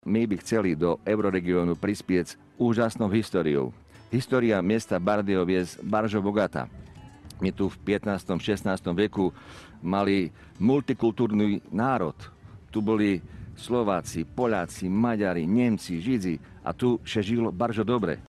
We wtorek Radio Biwak gościło na Słowacji. Nasze plenerowe studio stanęło w Rynku w Bardejowie.